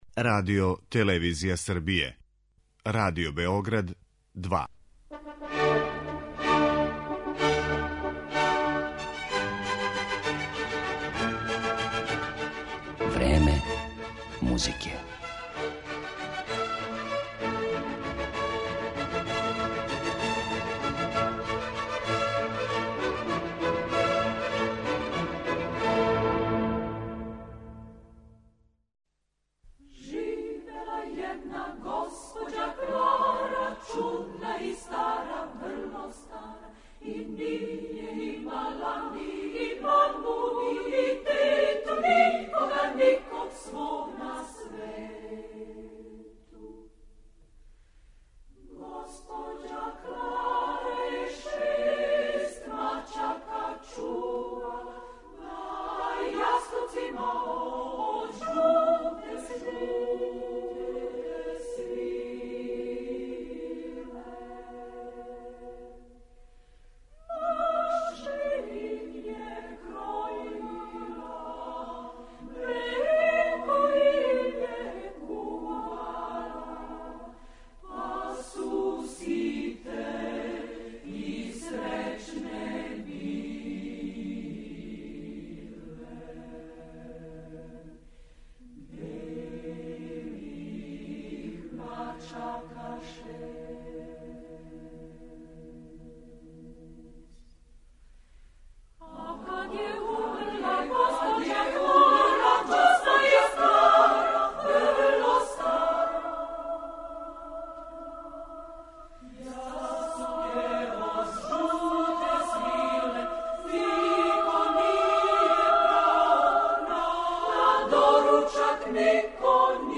Emisiju „Vreme muzike" posvetićemo kompozitoru Mihailu Vukdragoviću, koji je u periodu pred Drugi svetski rat bio na mestu šefa muzičkog programa Radio Beograda. Povodom 95. rođendana naše kuće, iz bogate arhive odabrali smo snimke na kojima Vukdragović govori o svom radu, kao i o godinama provedenim u Radio Beogradu.